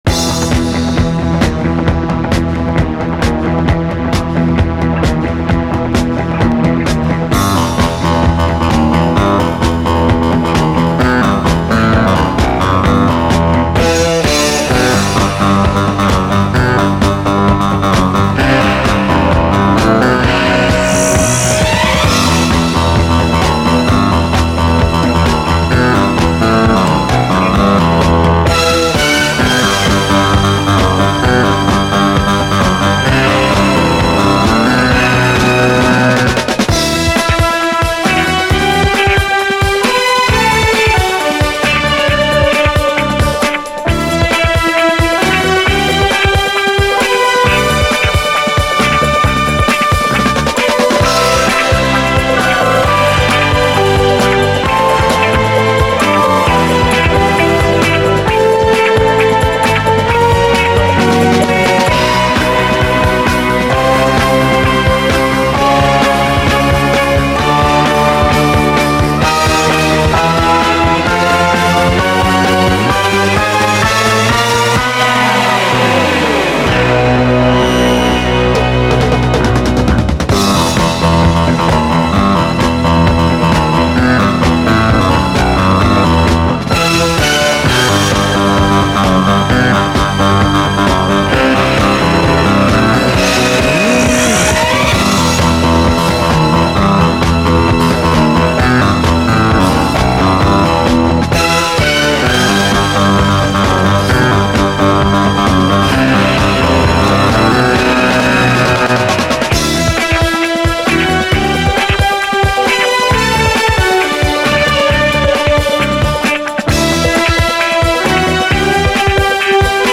SOUL, JAZZ FUNK / SOUL JAZZ, 70's～ SOUL, JAZZ, 7INCH
70’S刑事アクション・サントラ風のフランス産スリリング・ジャズ・ファンク！ライブラリー的な雰囲気。